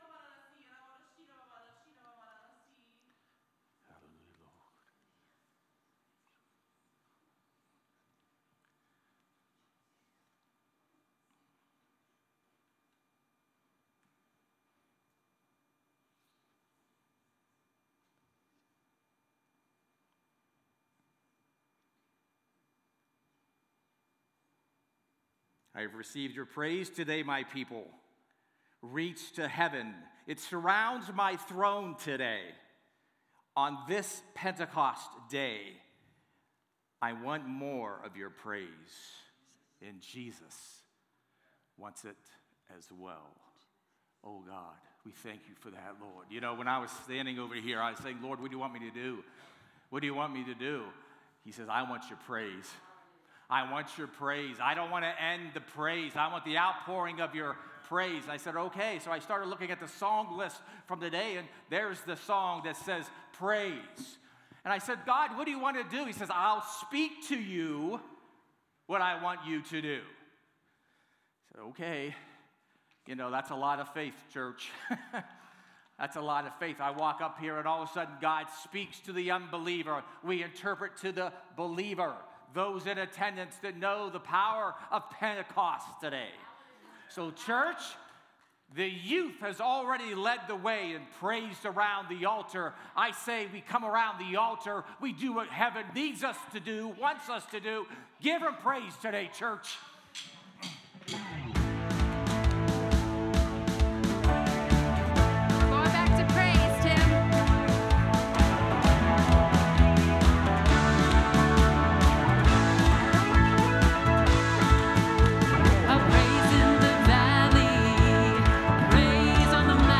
A message from the series "Summer Recharge."